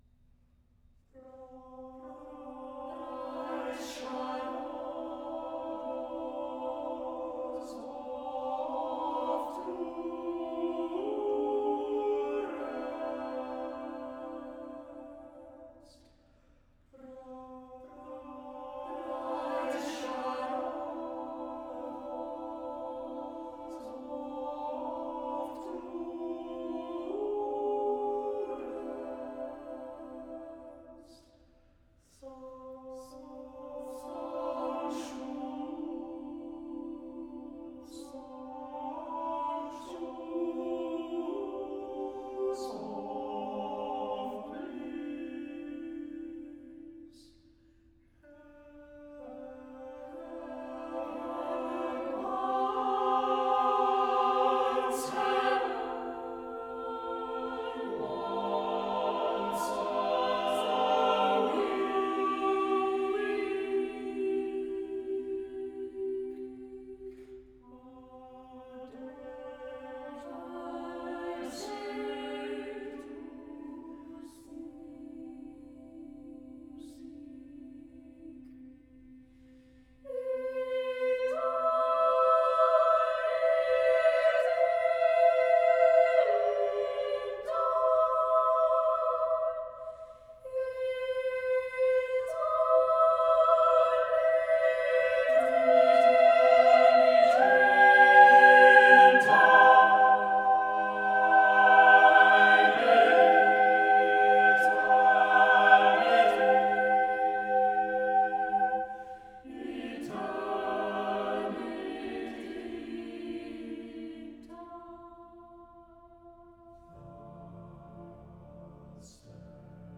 a haunting piece of choral perfection
Tags2010s 2013 Britain Choral modern